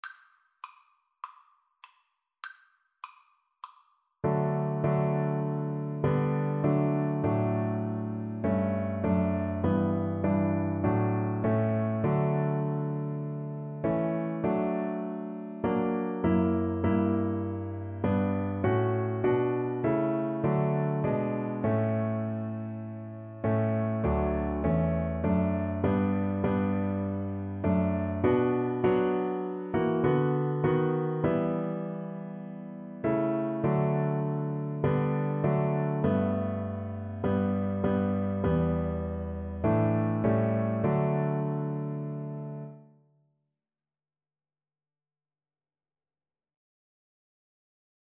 4/4 (View more 4/4 Music)
Classical (View more Classical Cello Music)